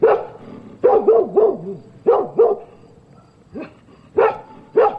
Index of /cianscape/birddataDeverinetal2025/File_origin/Noise-ESC-50/dog